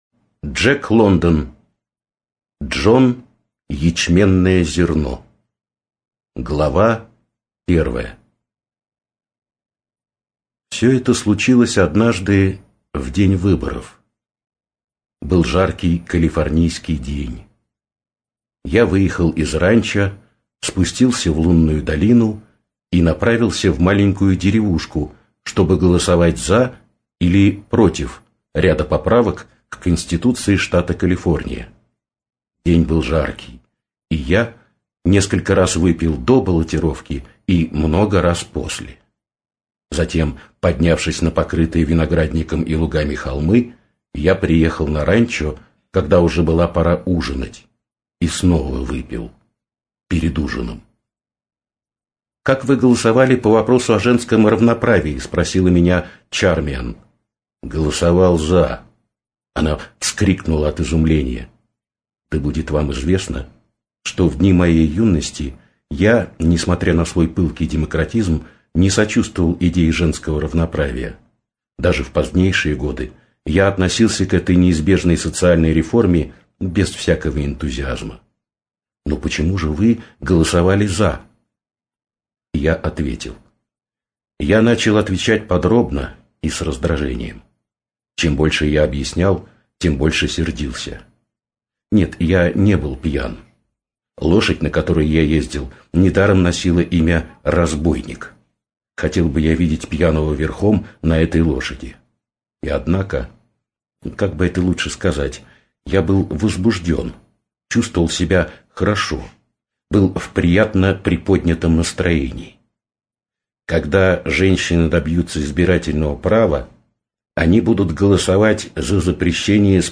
Аудиокнига Джон Ячменное Зерно (Воспоминания алкоголика) | Библиотека аудиокниг